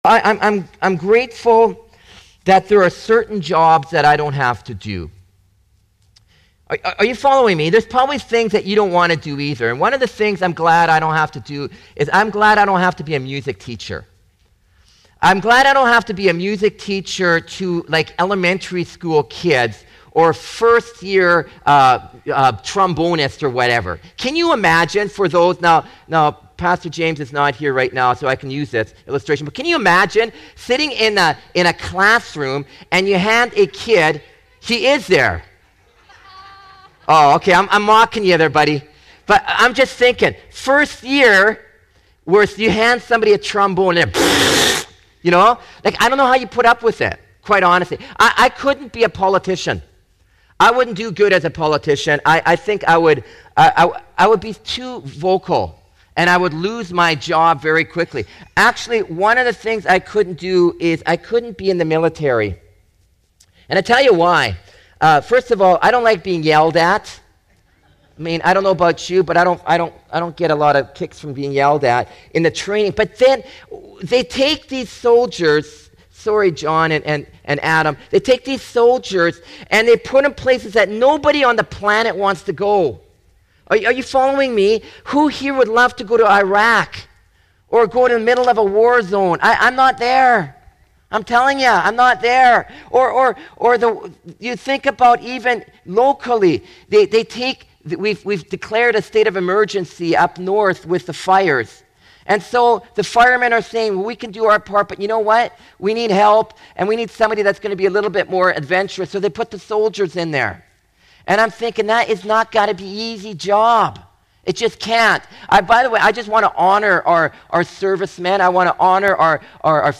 Sermons | Surrey Pentecostal Assembly